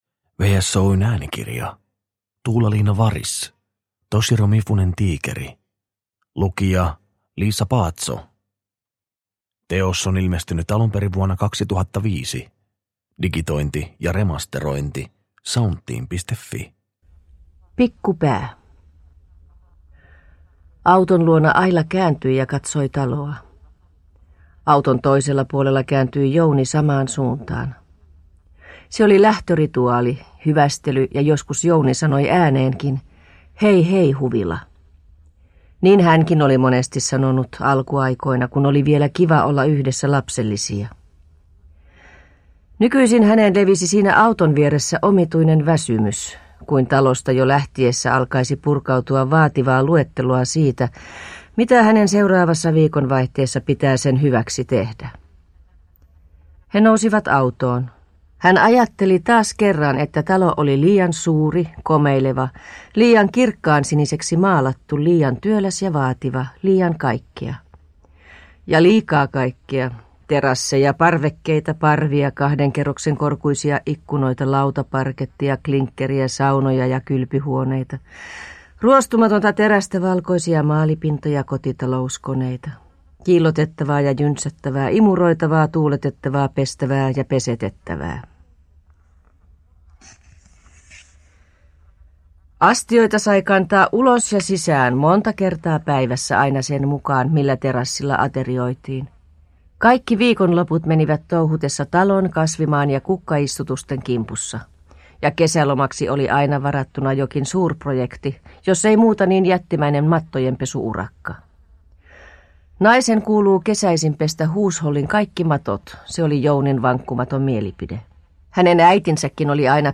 Toshiro Mifunen tiikeri – Ljudbok – Laddas ner